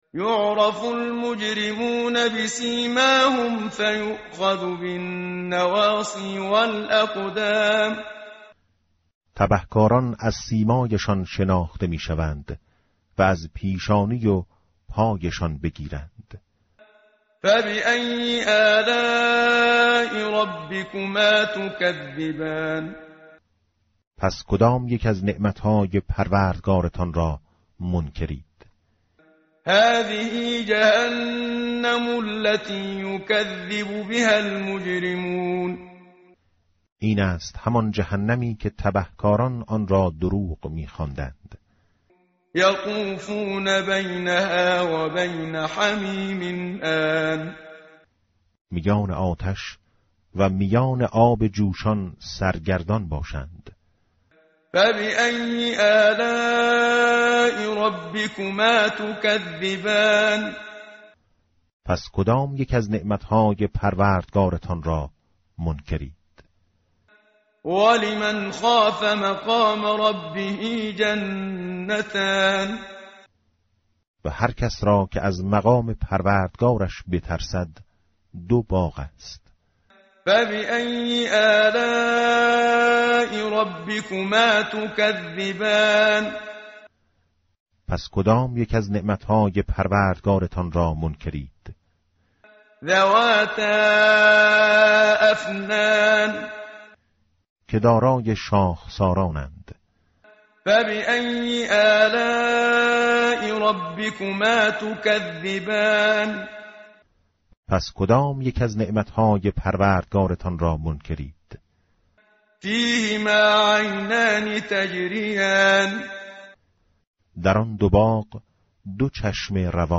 tartil_menshavi va tarjome_Page_533.mp3